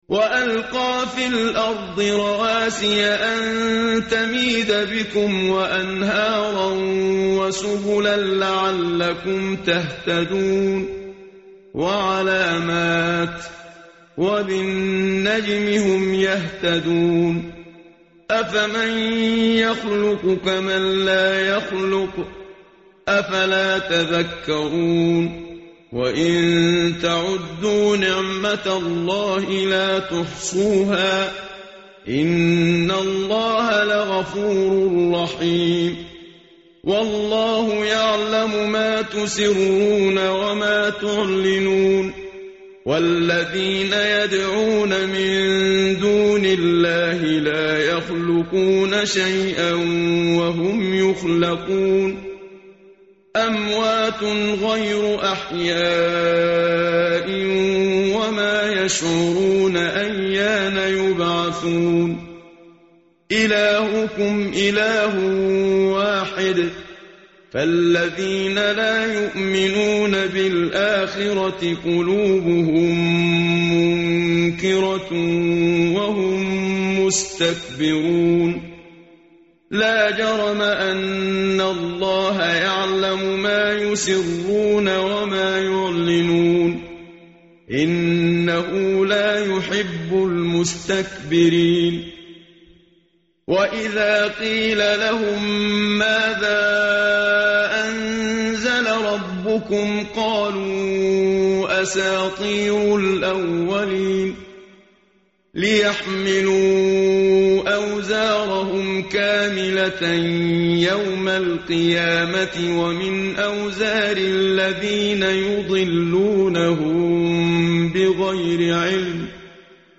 tartil_menshavi_page_269.mp3